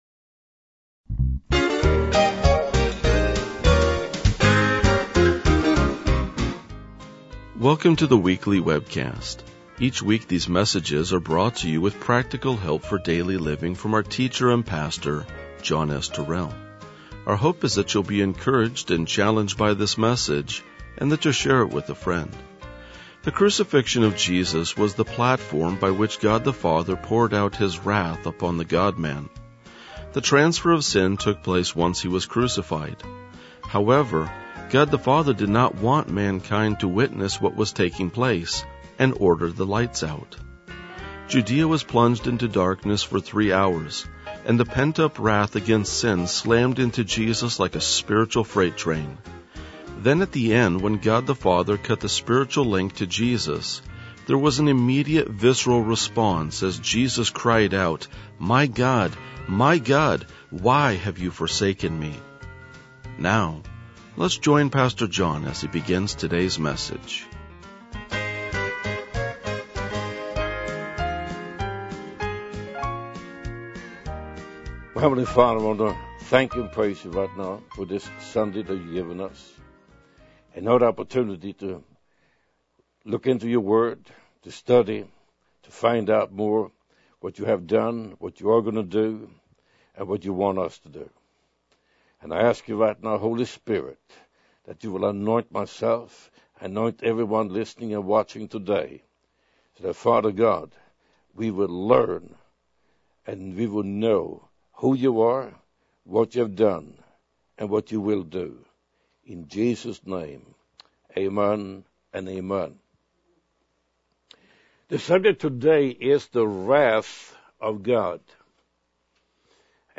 RLJ-1985-Sermon.mp3